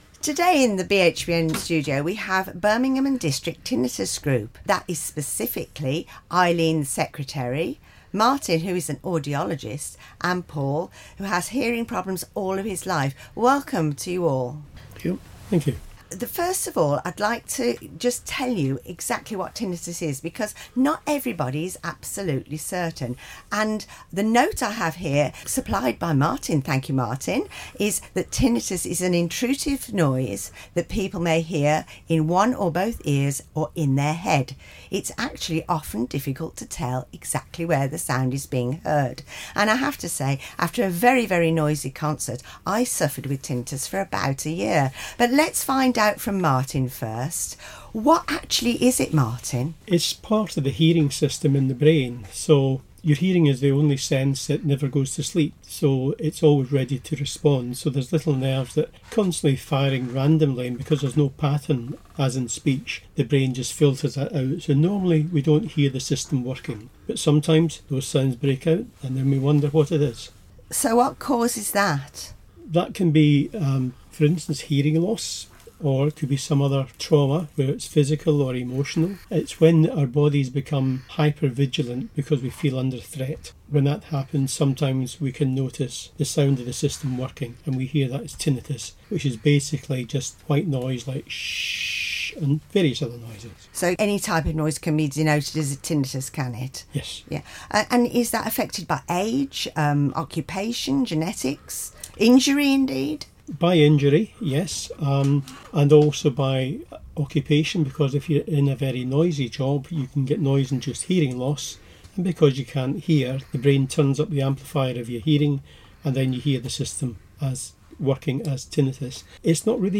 Birmingham Hospitals Broadcasting Network - Tinnitus Interview
Hospital Radio Inteview
Tinitus-Radio-Edit_1.mp3